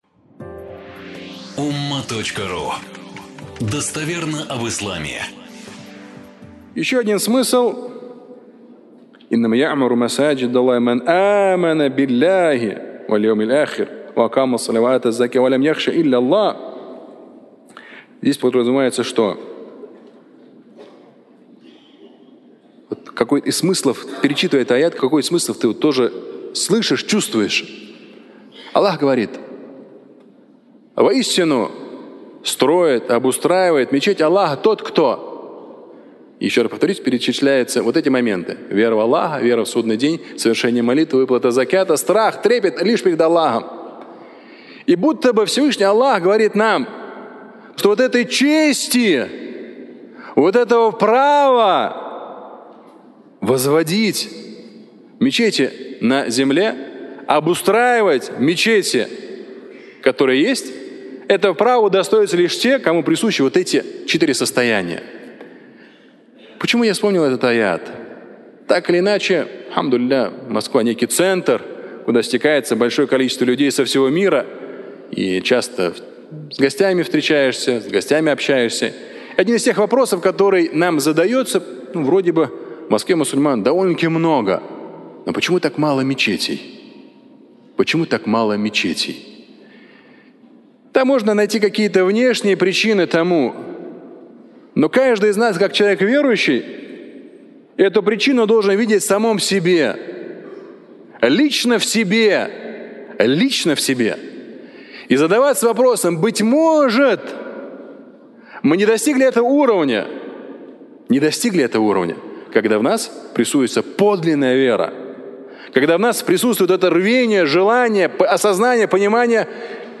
Фрагмент пятничной проповеди